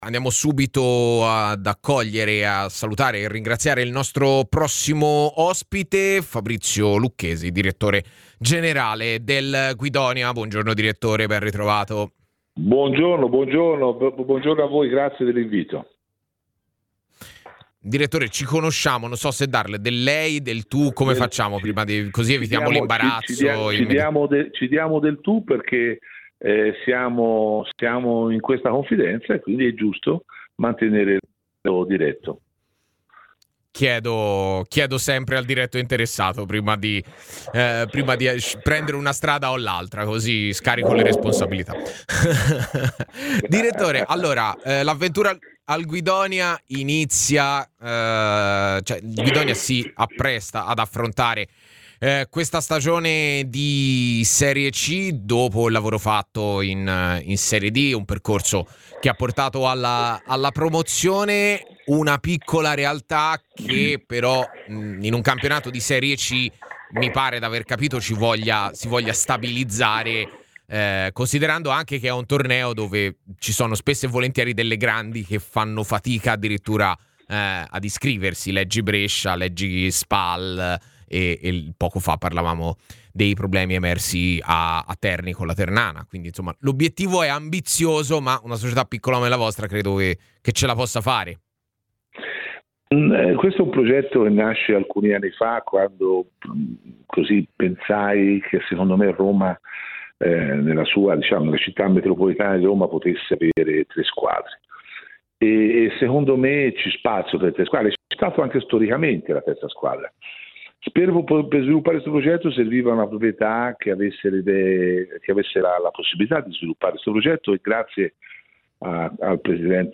intervenuto ai microfoni di TMW Radio , nel corso della trasmissione A Tutta C